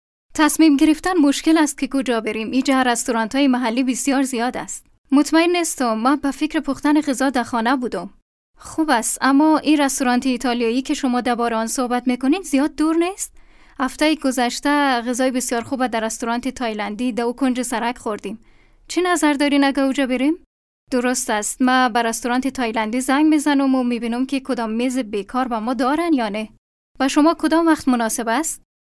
Female Child Young Adult